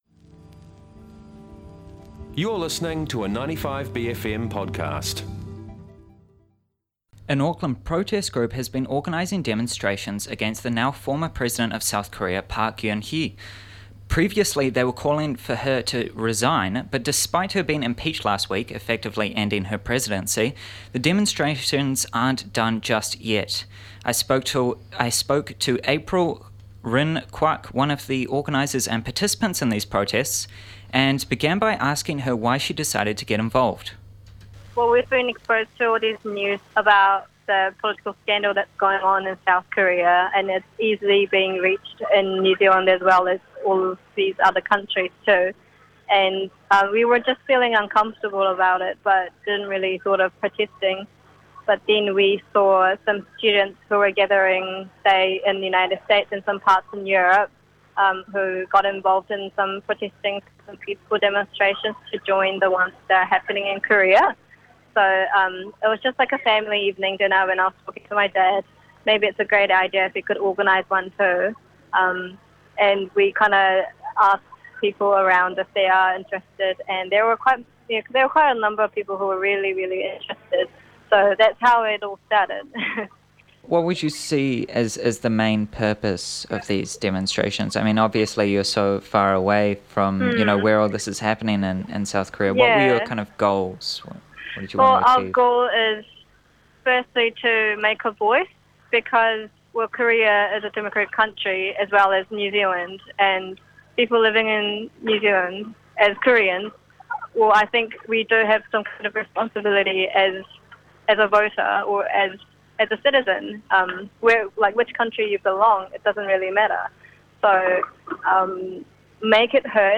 Demonstrations against President Park.mp3